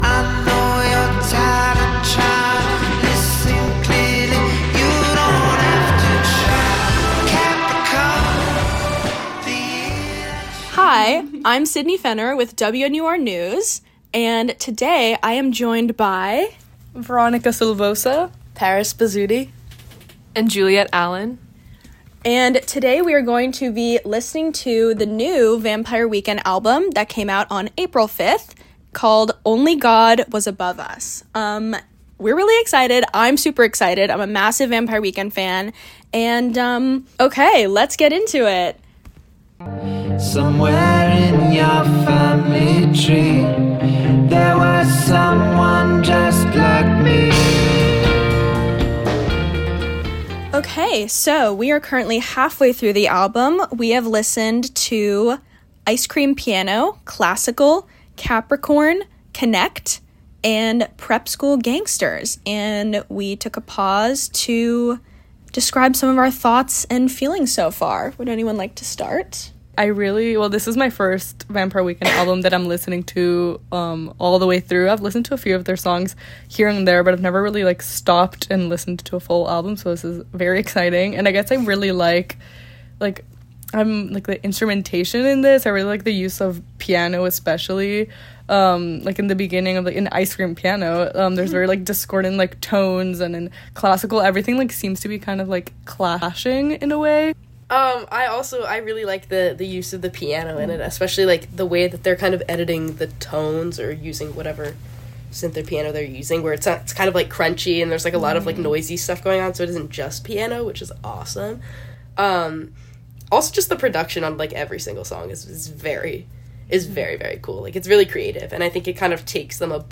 Vampire Weekend Album Review Roundtable: “Only God Was Above Us” – WNUR News